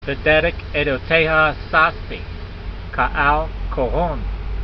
Sound (Psalm 119:14) Transliteration: be der ek aydo t ay ha sas tee ke' al kol - hon Vocabulary Guide: Living in the way of your testimoni es , I have exulted as having all riches . Translation: Living in the way of your testimonies, I have exulted as having all riches.